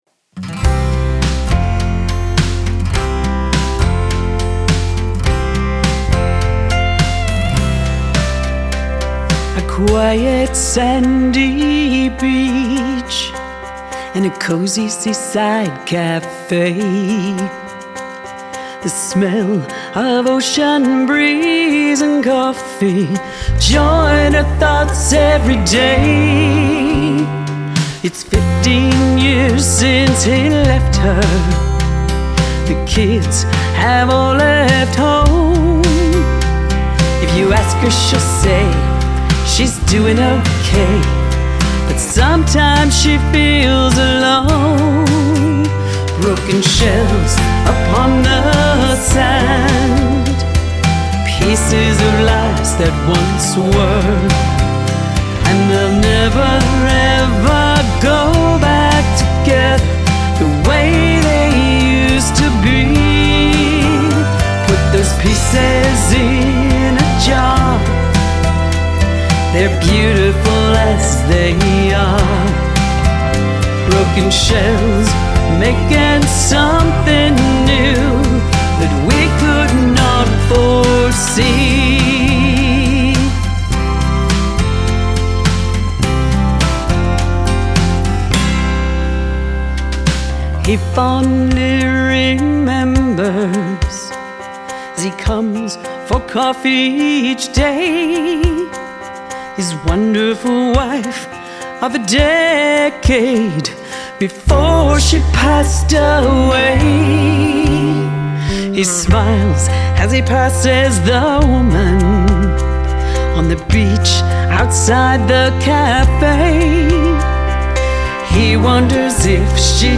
With its retro country feel